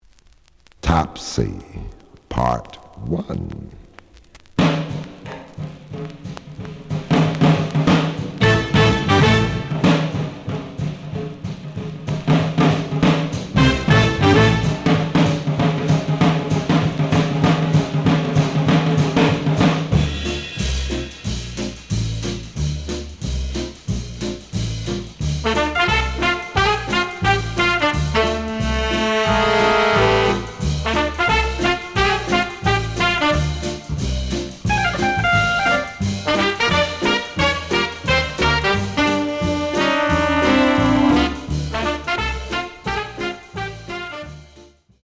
The audio sample is recorded from the actual item.
●Genre: Big Band